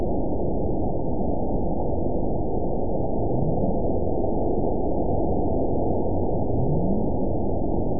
event 920492 date 03/27/24 time 23:18:11 GMT (1 month ago) score 9.32 location TSS-AB03 detected by nrw target species NRW annotations +NRW Spectrogram: Frequency (kHz) vs. Time (s) audio not available .wav